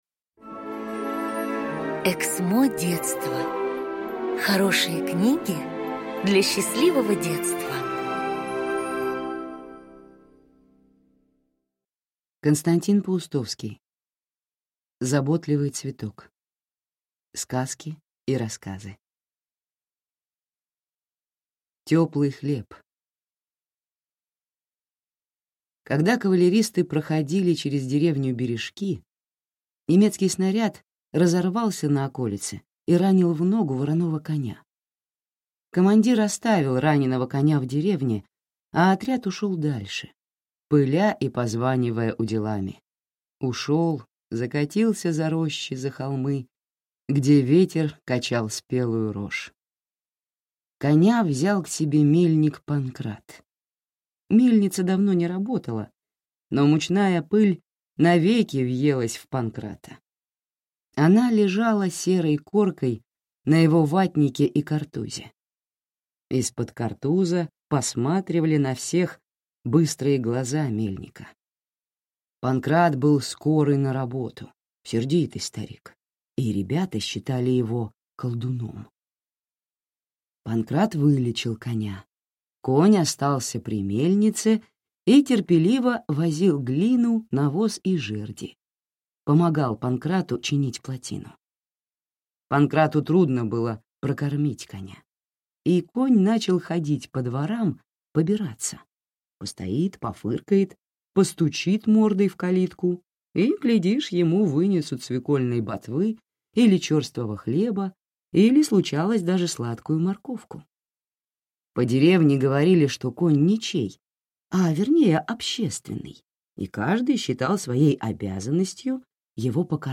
Аудиокнига Заботливый цветок | Библиотека аудиокниг